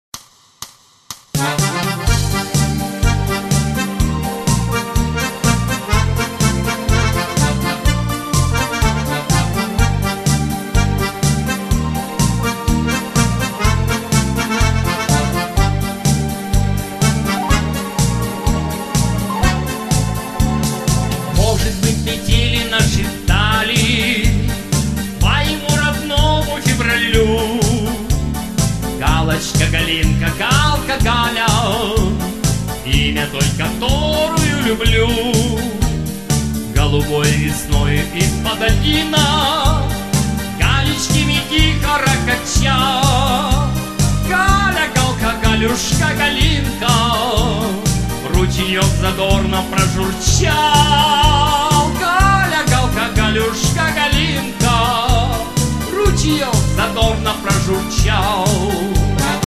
Демо-версии наших песен